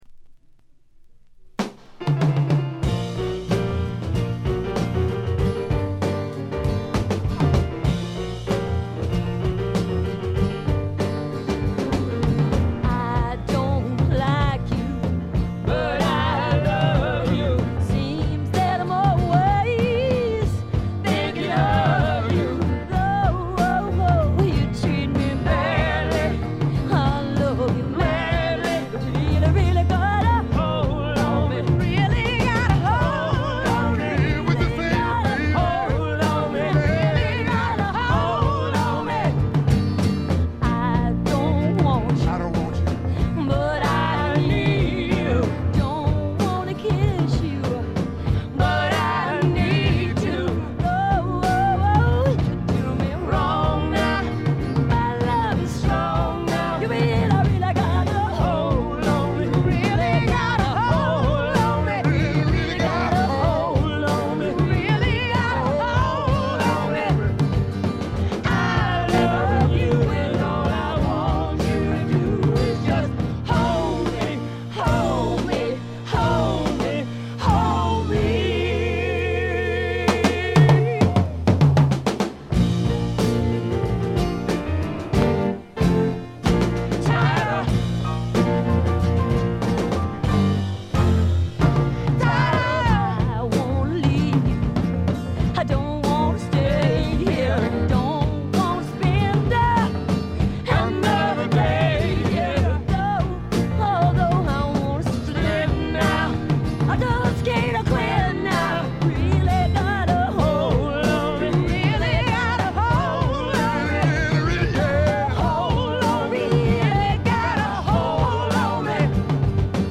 試聴曲は現品からの取り込み音源です。
※B1-B2連続です。曲間前後で周回ノイズ。